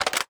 m82_magout.wav